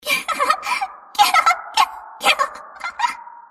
Attack
Enemy_Voice_PT_Imp_Pack_Attack.mp3